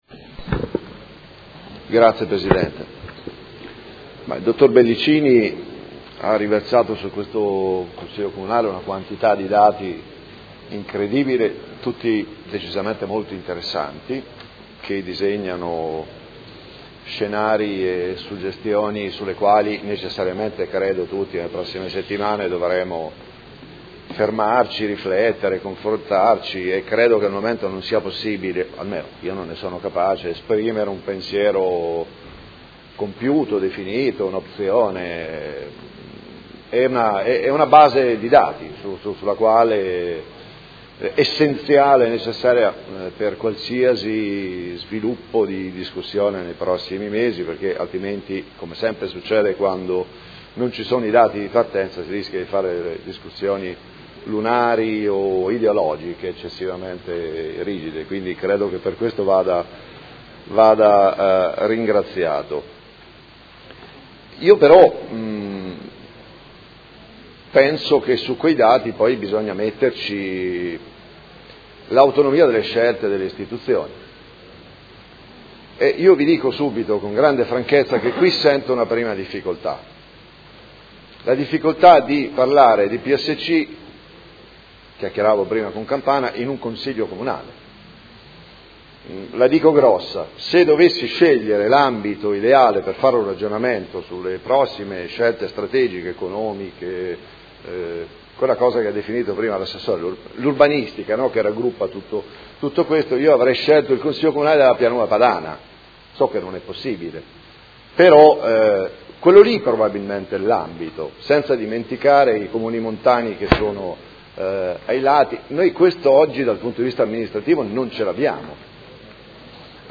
Seduta del 16/03/2017. Dibattito su 1° INCONTRO DEL PERCORSO VERSO IL NUOVO PIANO URBANISTICO